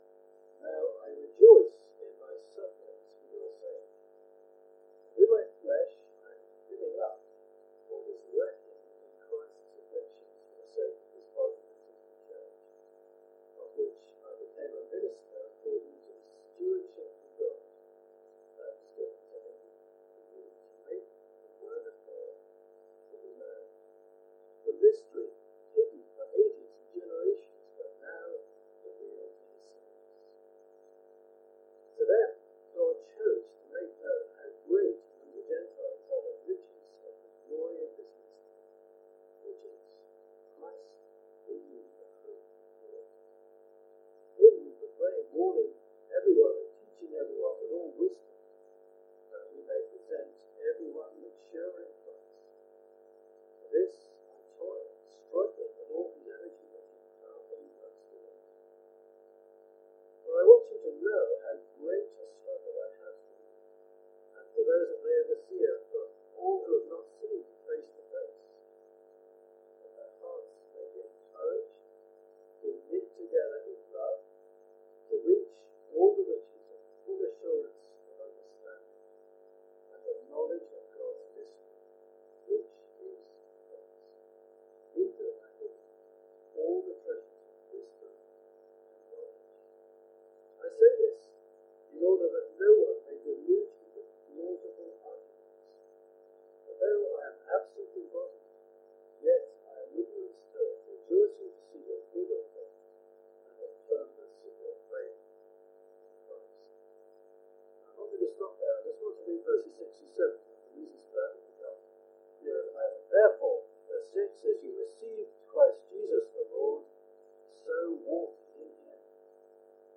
Sermons | St Andrews Free Church
Apologies for the poor audio quality.